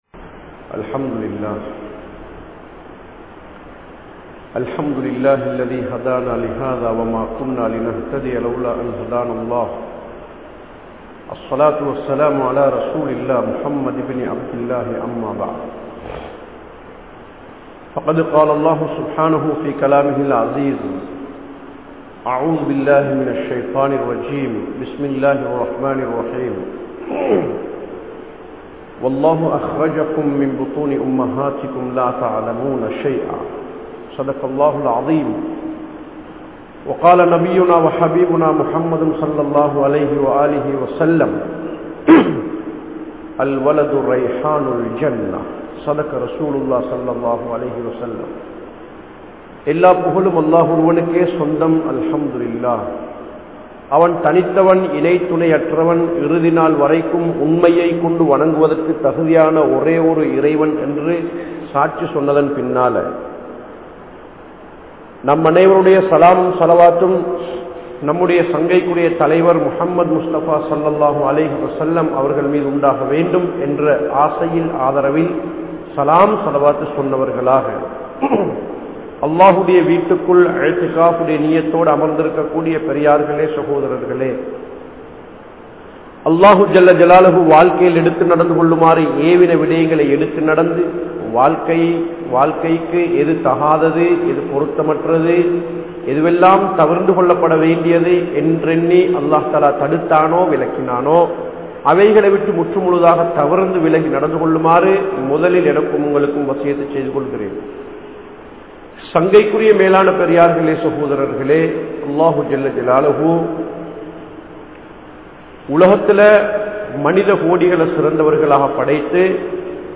Pillai Perum Puthu Murai | Audio Bayans | All Ceylon Muslim Youth Community | Addalaichenai
Gorakana Jumuah Masjith